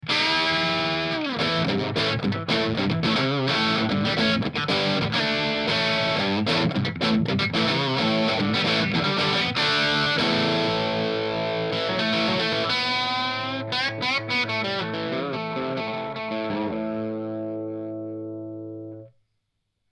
L’ampli, comme d’hab, un clone de JTM45 sur un 2×12 greenback. Les distos sont obtenues via une OCD (fulltone), rien de plus, même pas de reverb…
C’est un ampli très crémeux, pas agressif comme peuvent l’être certains JTM45.
Un ampli bien blues comme il faut !
truebucker_crunch_chevalet.mp3